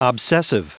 Prononciation du mot obsessive en anglais (fichier audio)
Prononciation du mot : obsessive